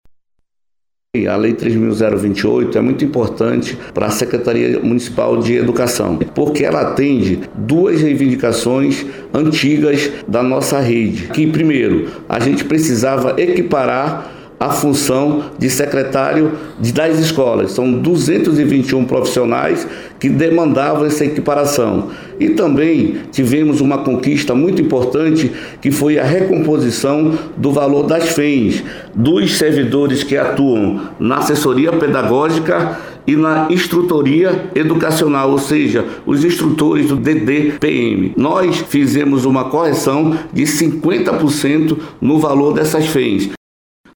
Como explica o subsecretário municipal de Administração e Finanças da Semed, Lourival Praia.